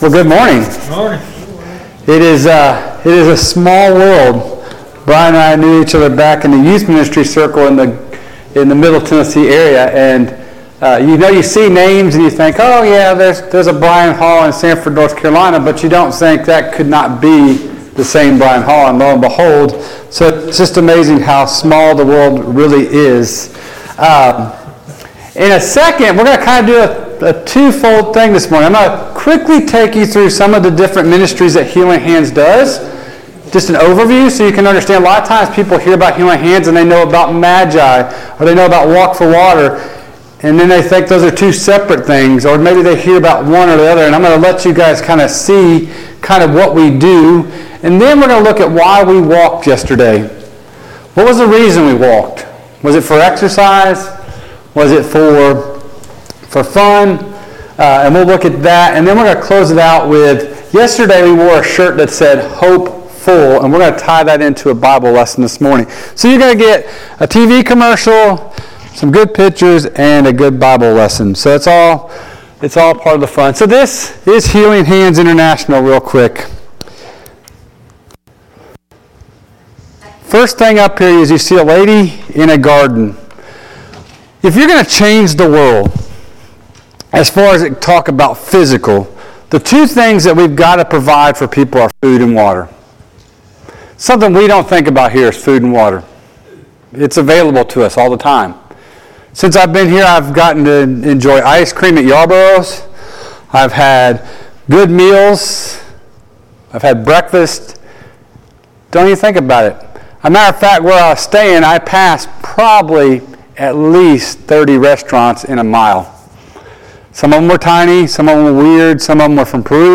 Sunday Morning Bible Class Download Files Notes Topics